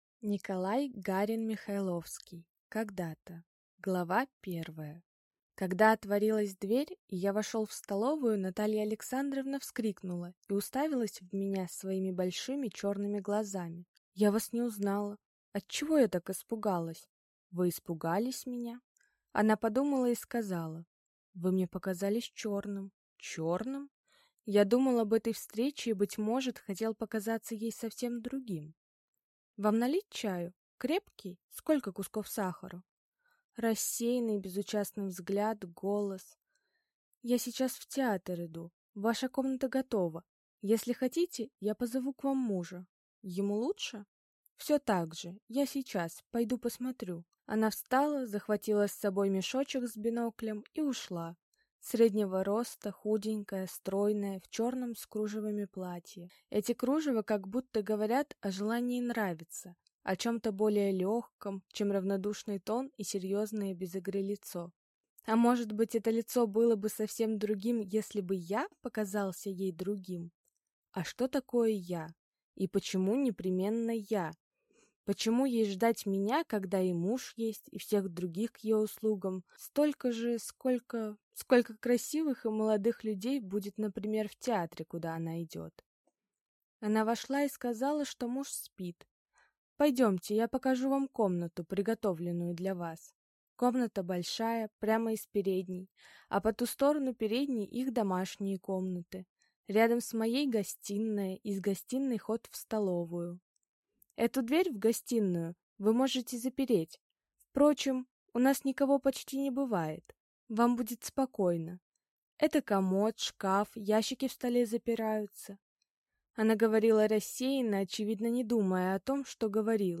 Аудиокнига Когда-то | Библиотека аудиокниг